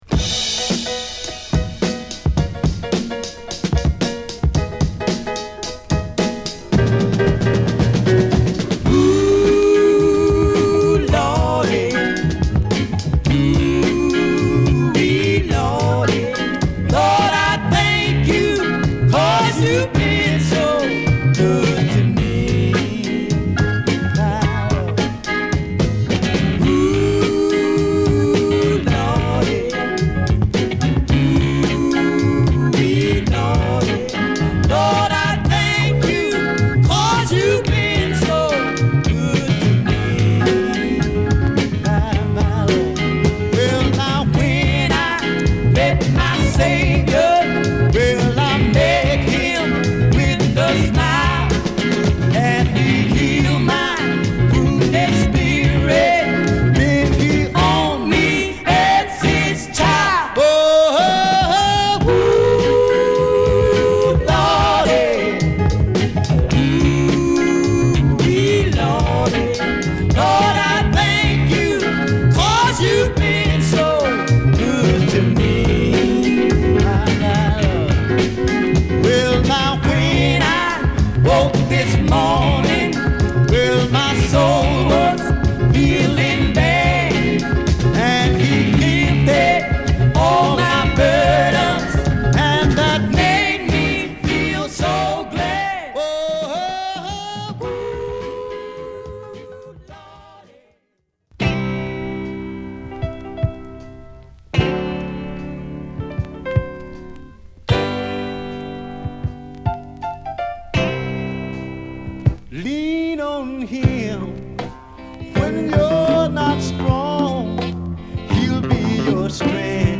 Funky Gospel